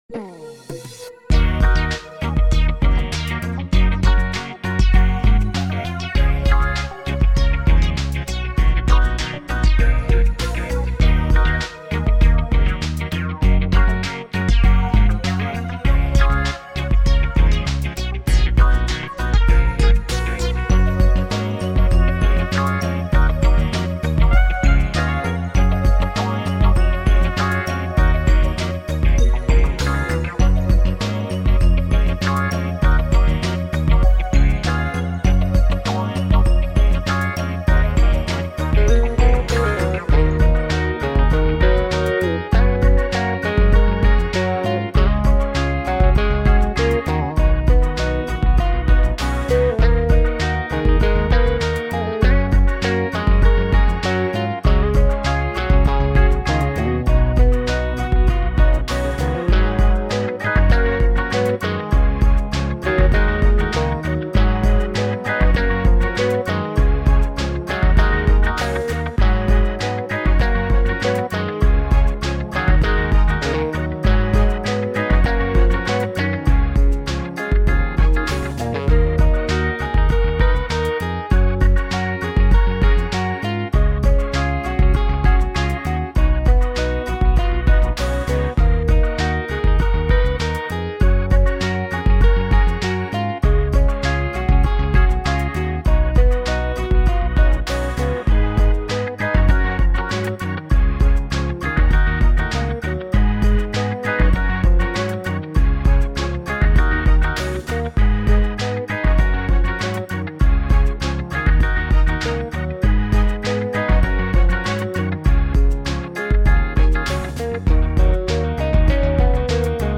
A guitar-driven take on Amiga mods